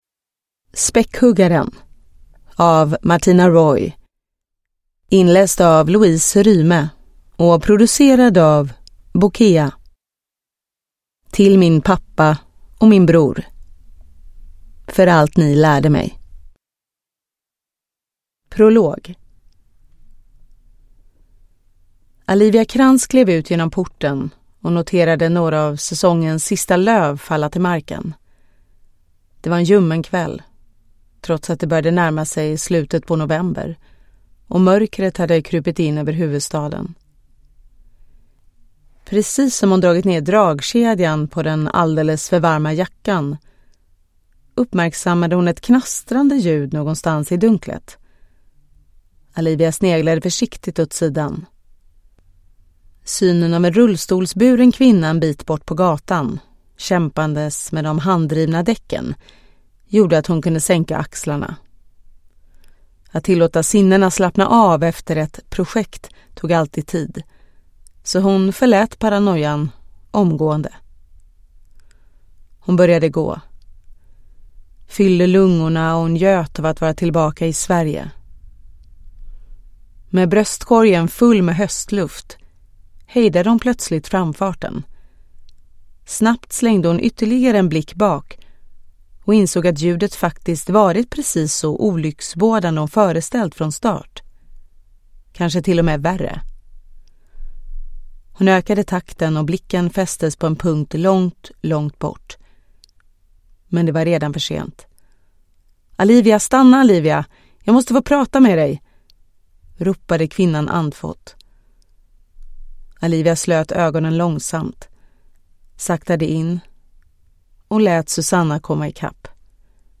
Späckhuggaren (ljudbok) av Martina Royy